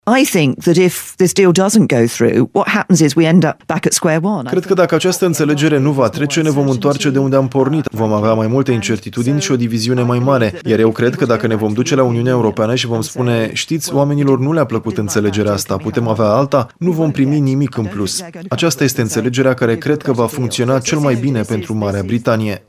Pe fondul ameninţărilor din partea oponenţilor politici, doamna May a încercat ieri să convingă publicul britanic, într-o dezbaterea la BBC, că acordul de separare convenit cu Uniunea este cel mai bun posibil.